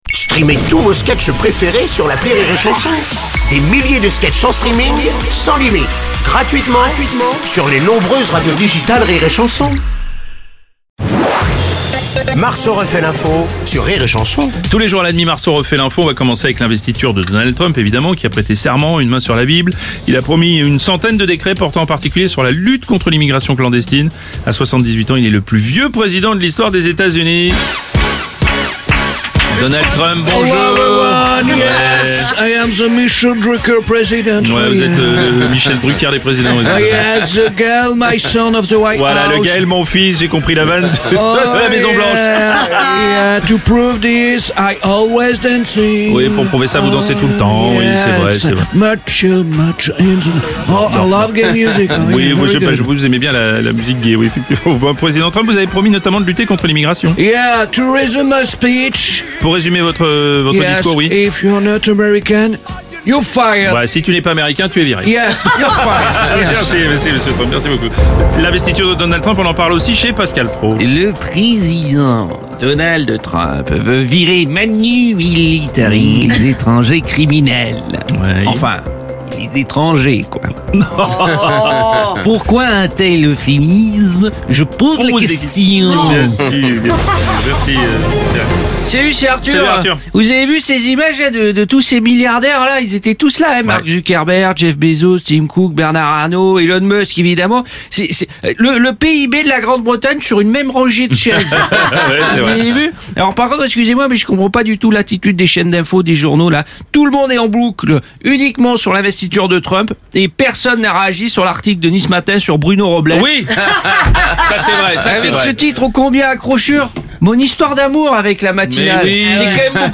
Comédie pour toute la famille Divertissement Rire et Chansons France Chansons France Tchat de Comédiens Comédie
L’imitateur